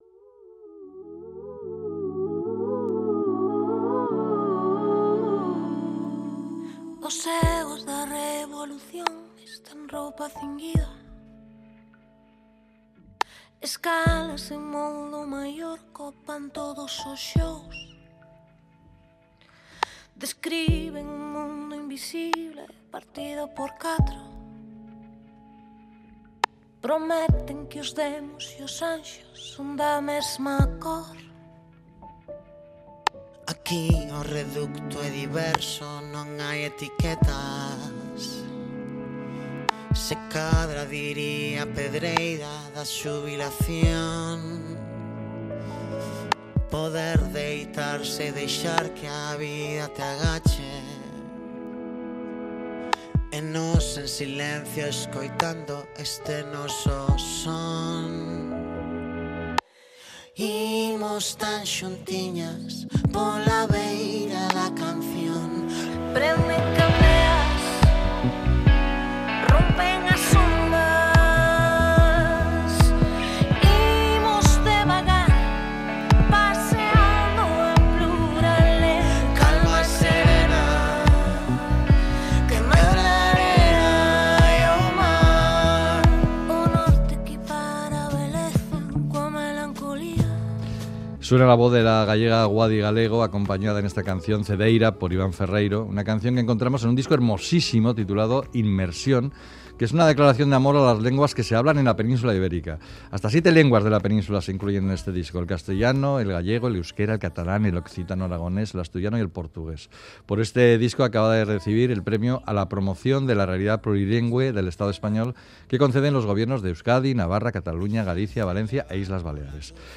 Audio: Charlamos con Guadi Galego de su maravilloso disco "Immersion", un homenaje a las lenguas que se hablan en la península ibérica, un canto a la diversidad